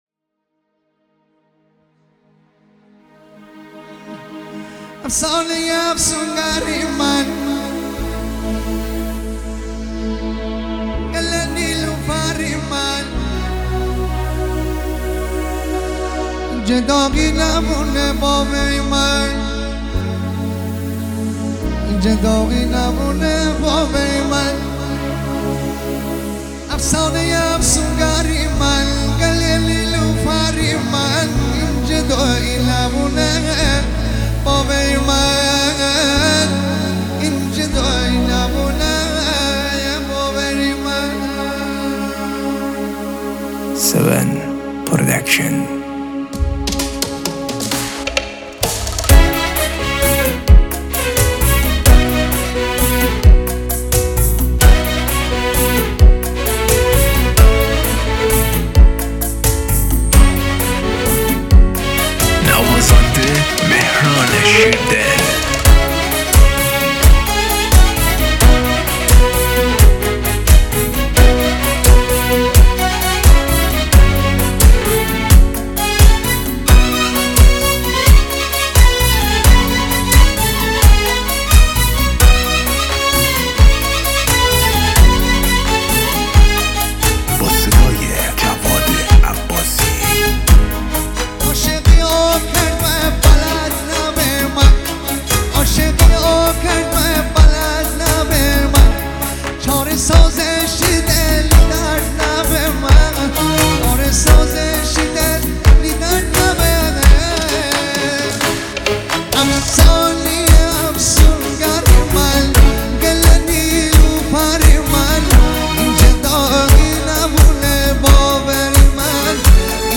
جشنی مجلسی